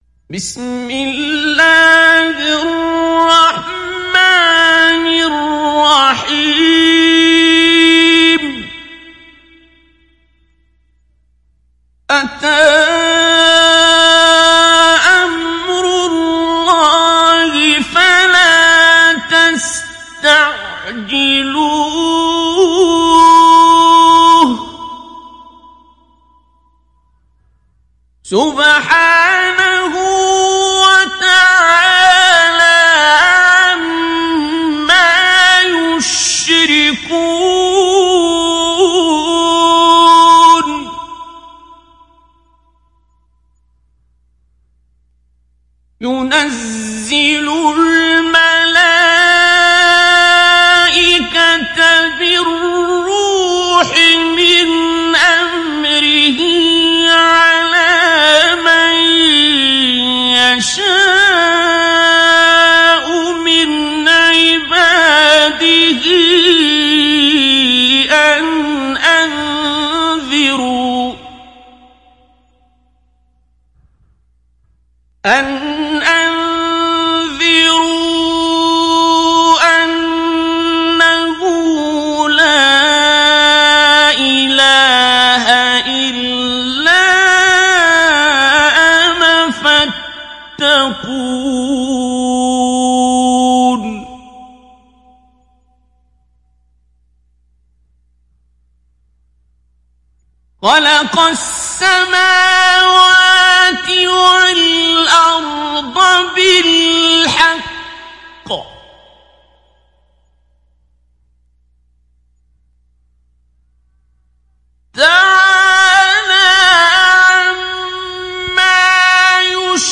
دانلود سوره النحل عبد الباسط عبد الصمد مجود